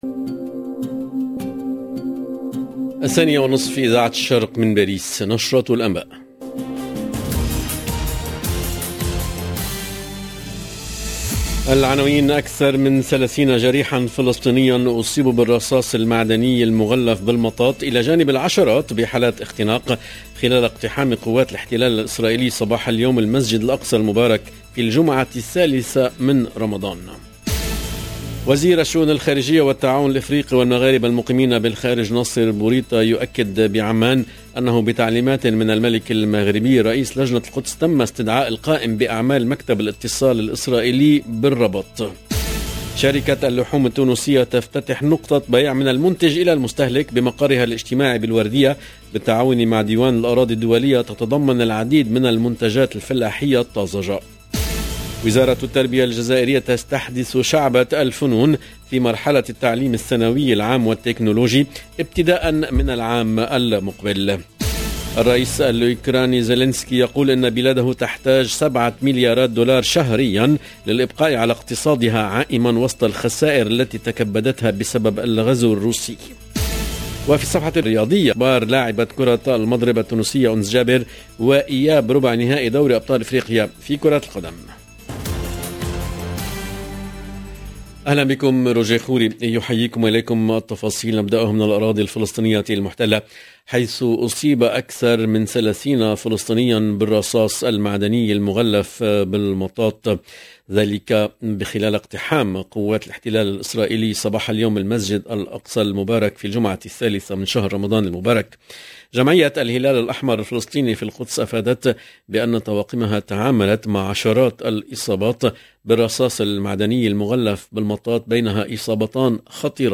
LB JOURNAL EN LANGUE ARABE